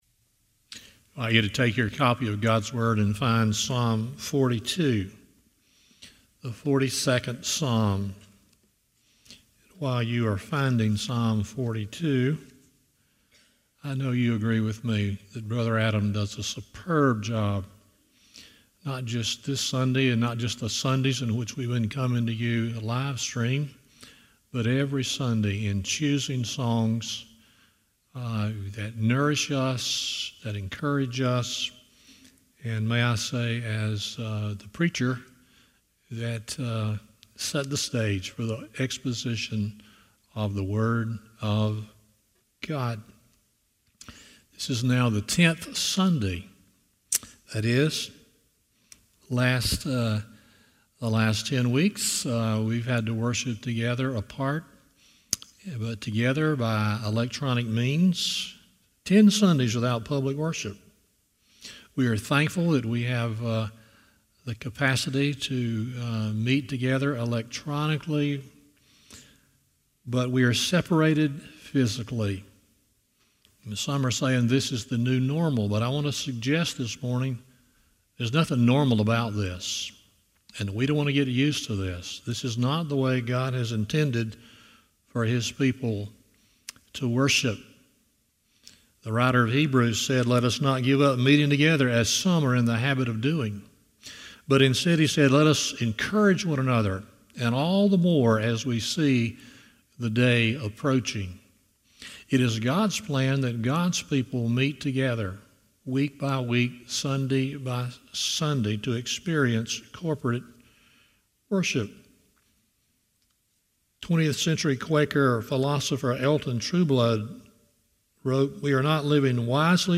Psalm 42:1-11 Service Type: Sunday Morning 1.